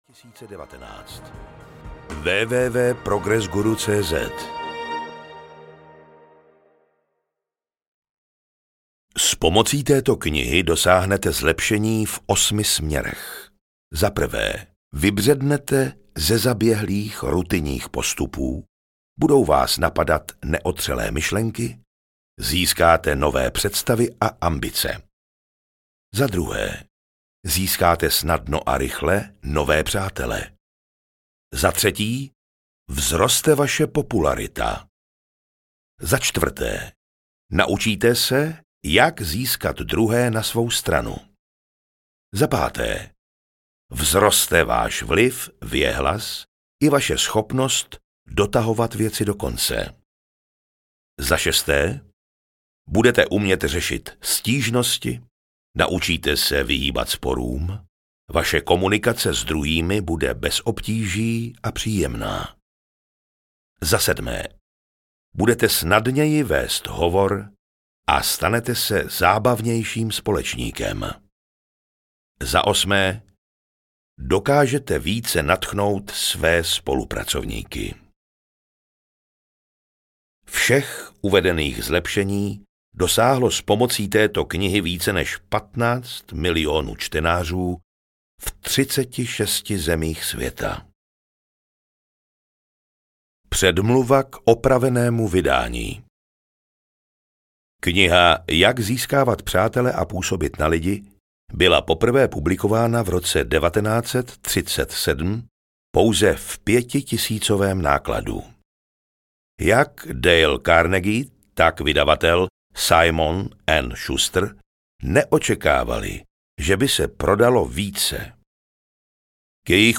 Jak získávat přátele a působit na lidi audiokniha
Ukázka z knihy